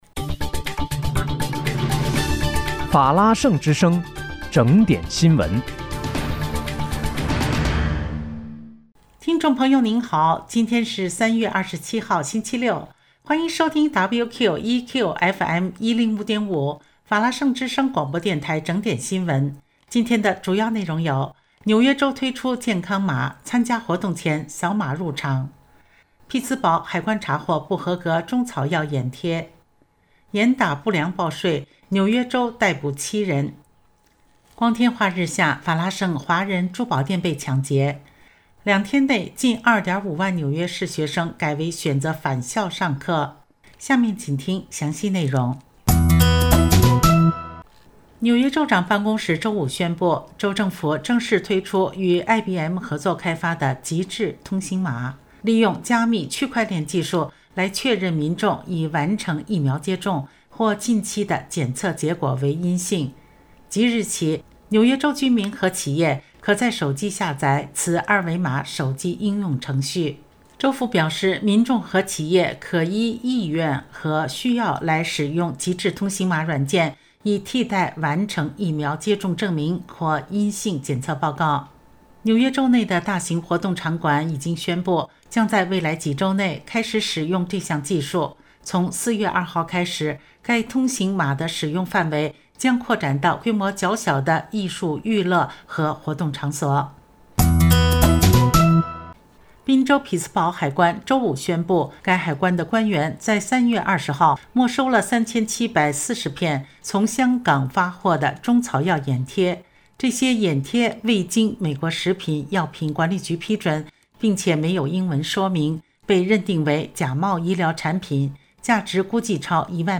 3月27日（星期六）纽约整点新闻